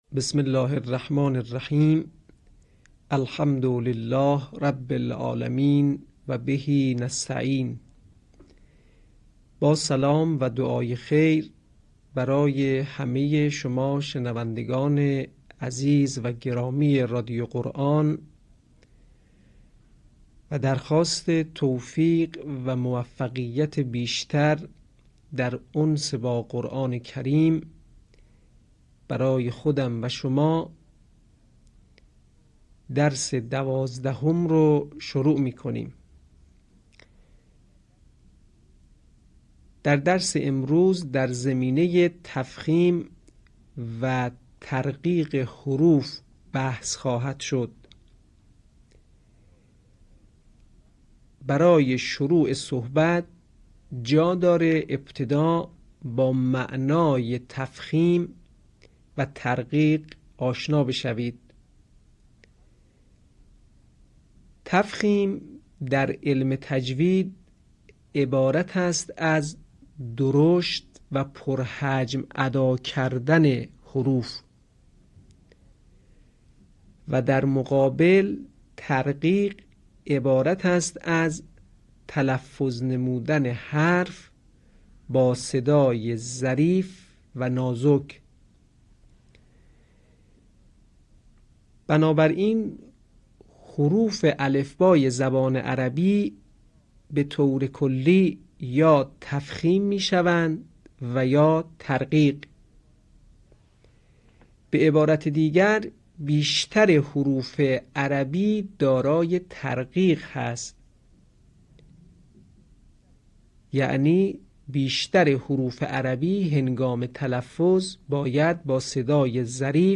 صوت | آموزش تفخیم و ترقیق حروف